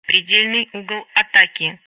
C'est vrai que moi aussi la petite voix m'a fait tilte !